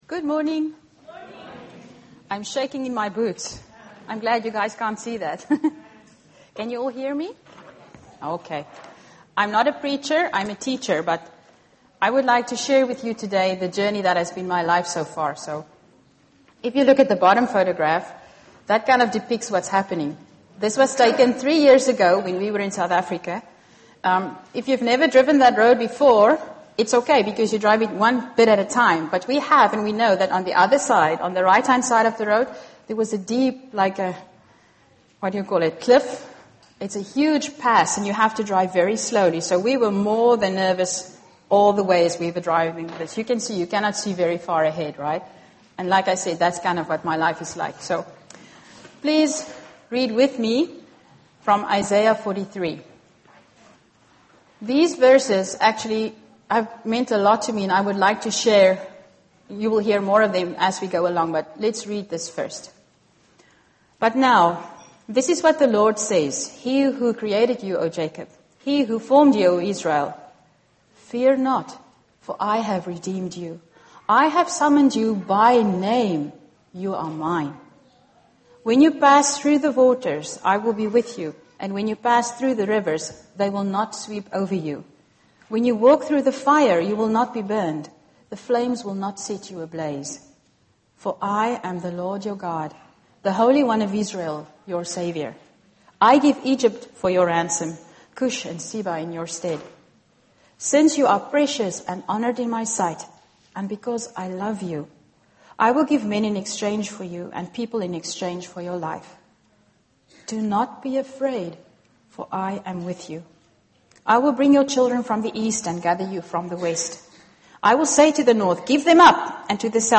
Audio recordings and transcripts of Bible messages shared at OIC.